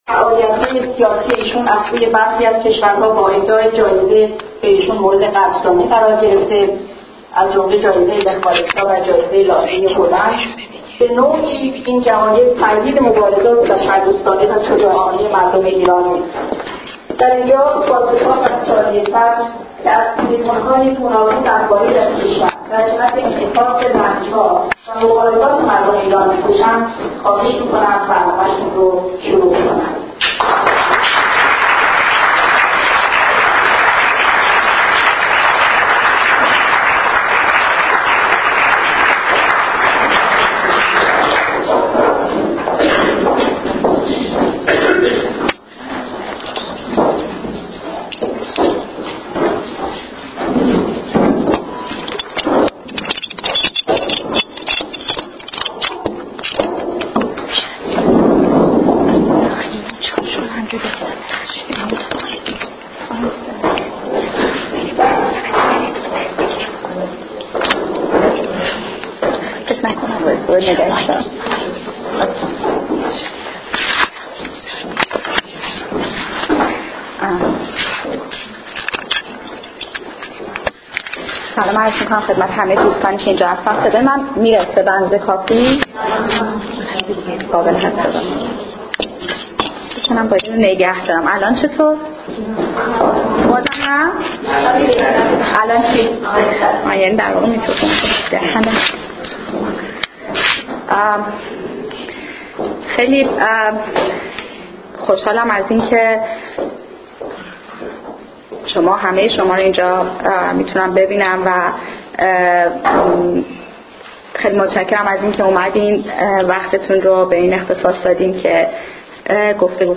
سخنرانی شادی صدر در مورد خشونت بر زنان در کلن